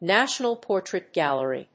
アクセントNátional Pórtrait Gàllery